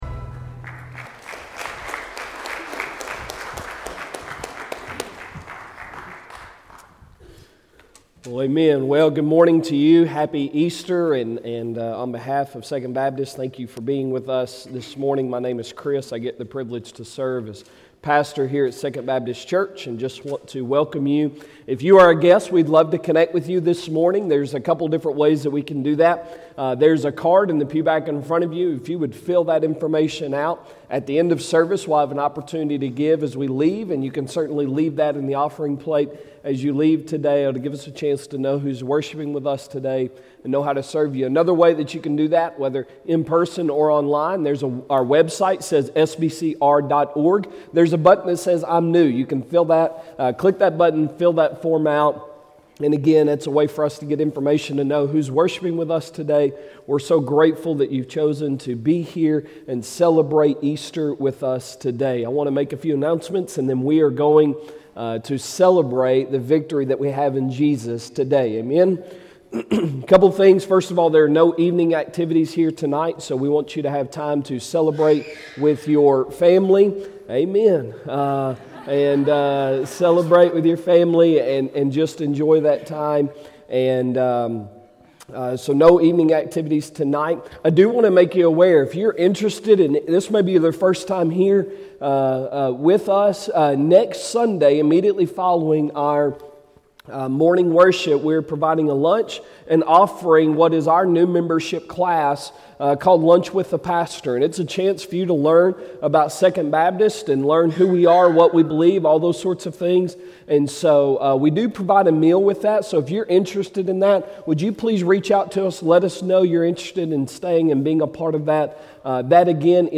Sunday Sermon April 17, 2022